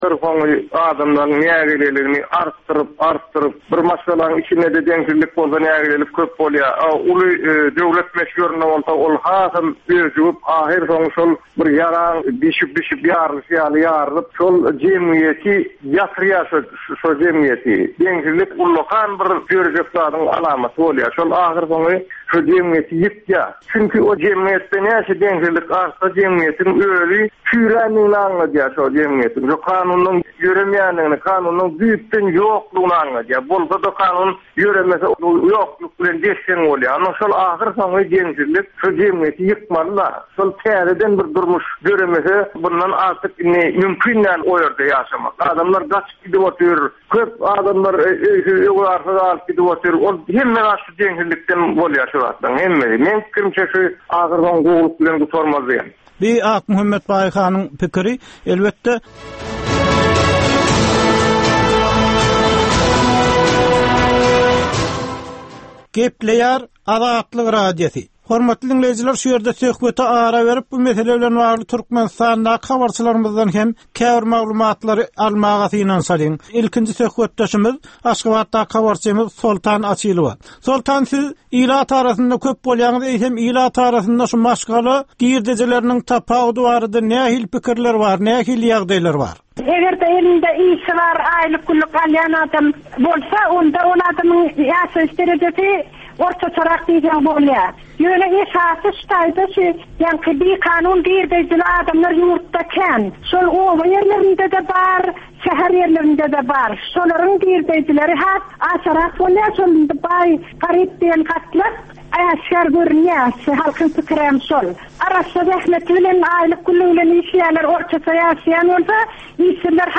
Türkmenistanly synçylaryň gatnaşmagynda, ýurduň we halkyň durmuşyndaky iň möhüm meseleler barada töwerekleýin gürrüň edilýän programma. Bu programmada synçylar öz pikir-garaýyşlaryny aýdyp, jedelleşip bilýärler.